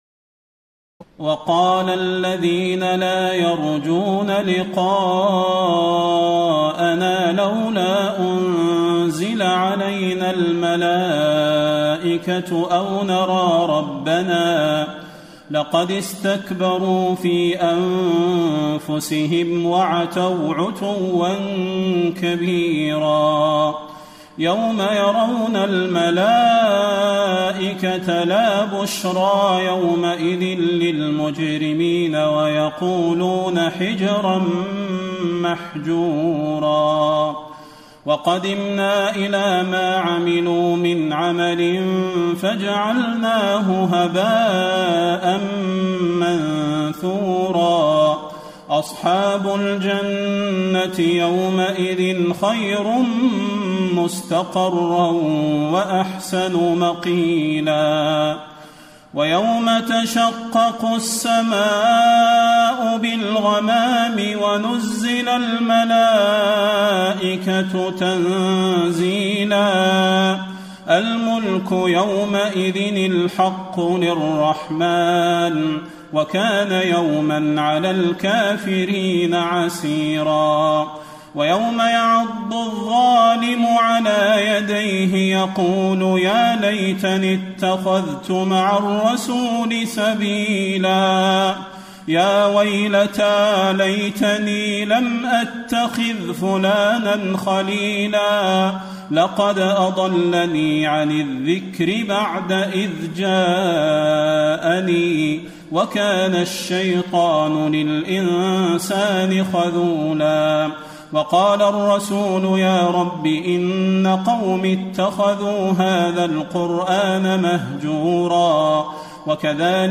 تراويح الليلة الثامنة عشر رمضان 1434هـ من سورتي الفرقان (21-77) و الشعراء (1-104) Taraweeh 18 st night Ramadan 1434H from Surah Al-Furqaan and Ash-Shu'araa > تراويح الحرم النبوي عام 1434 🕌 > التراويح - تلاوات الحرمين